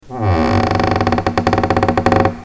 porte grince.mp3
porte-grince.mp3